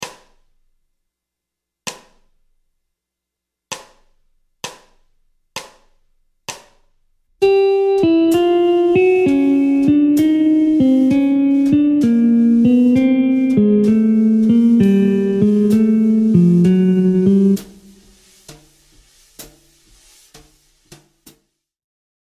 Gamme mineure harmonique ( I – mode mineur harmonique)
Descente de gamme
Gamme-bop-desc-Pos-42-C-min-harm.mp3